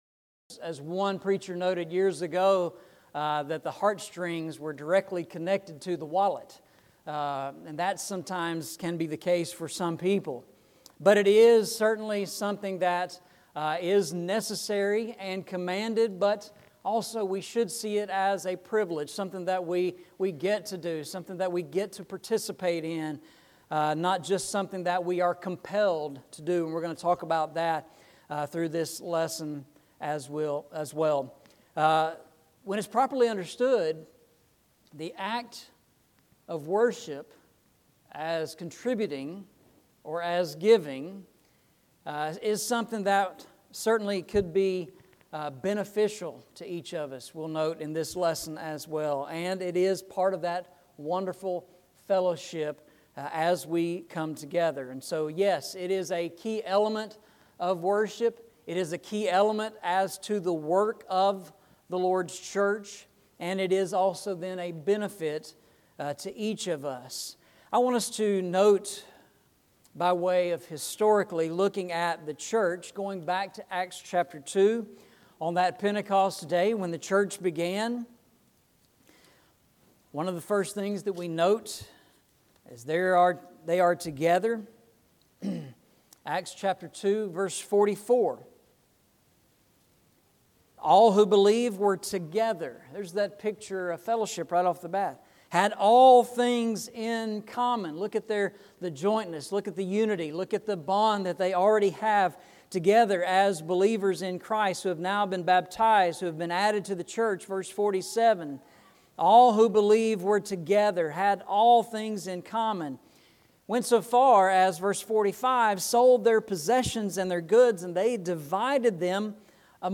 Eastside Sermons Passage: 1 Corinthians 16:1-2 Service Type: Sunday Morning « Lest Satan Should Take Advantage of Us Do You Have Vision?